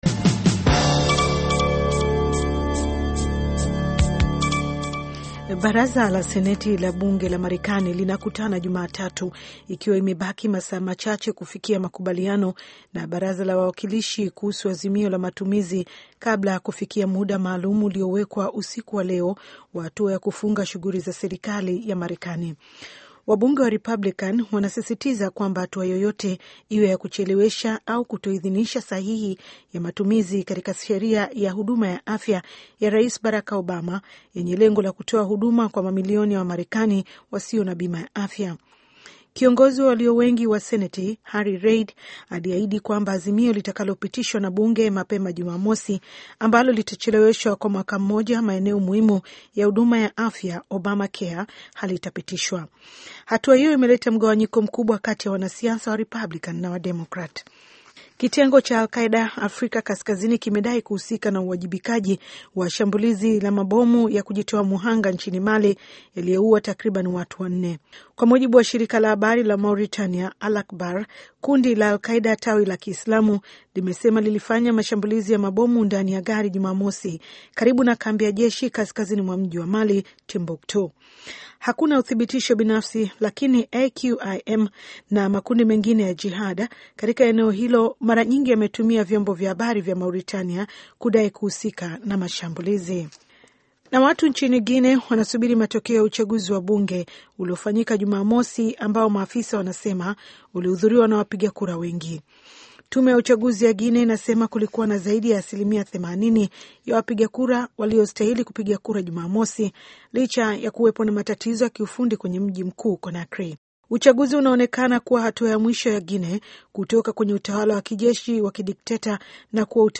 Taarifa ya Habari VOA Swahili - 6:00